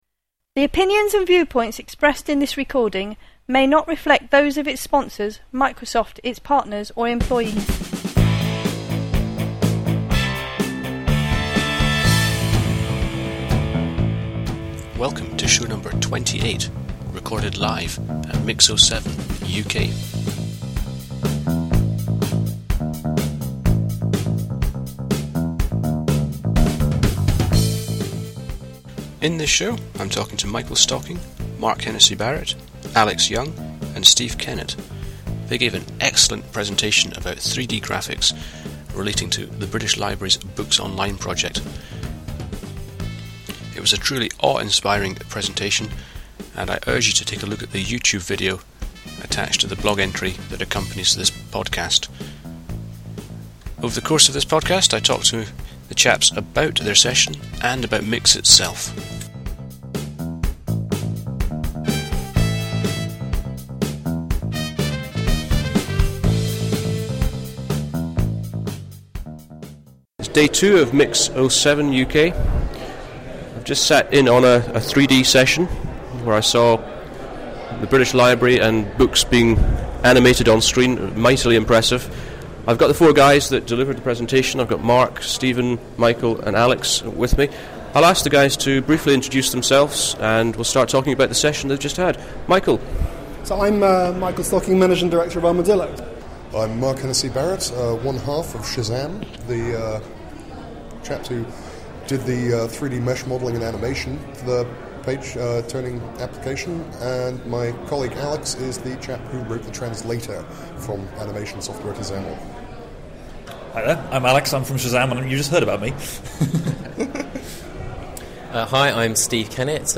This is podcast #28 – I’m talking to the team behind the British Library’s Turning The Page project.
MIX in Las Vegas